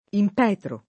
impetrare v. (lett. «ottenere con preghiere»); impetro [
imp$tro] — es.: Sì dolcemente che mercé m’impetre [S& ddol©em%nte ke mmer©% mm imp$tre] (Petrarca); se di vecchiezza La detestata soglia Evitar non impetro [